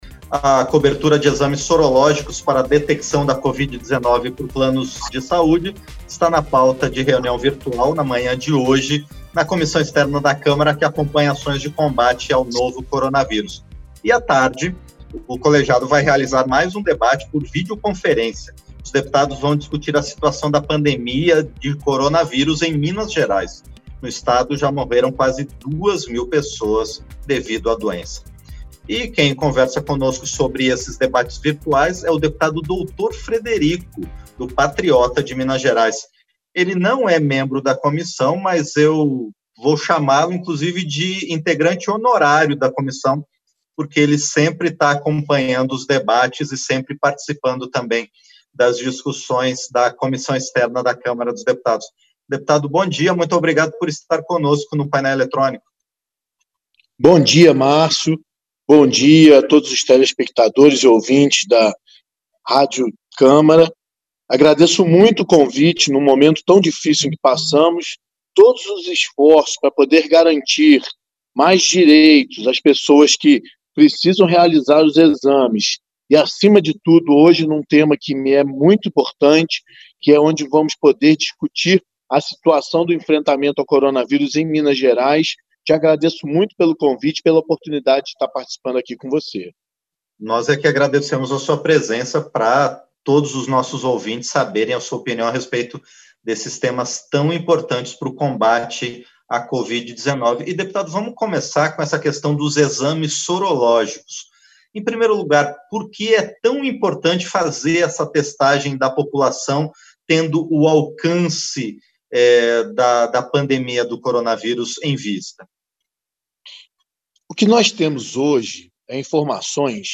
Entrevista - Dep. Dr. Frederico (PATRIOTA-MG)